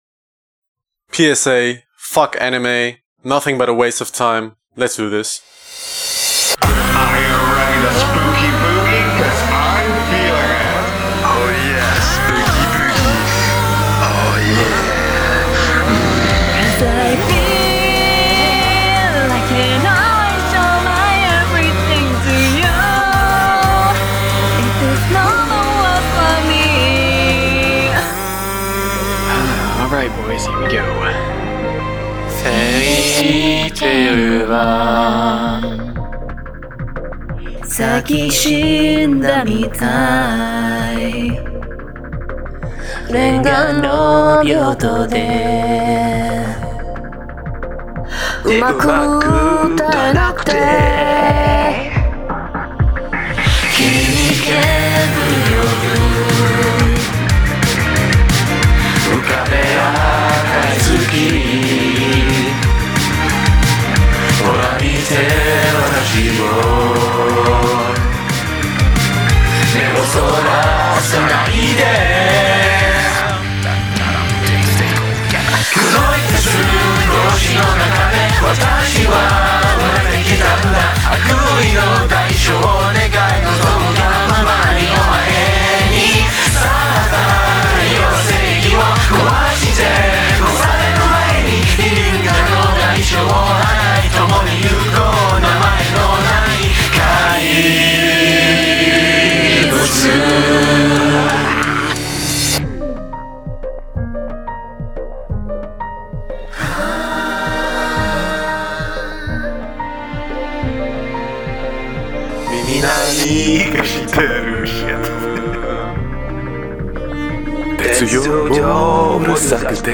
It also seems like Youtube doesn't like loud content.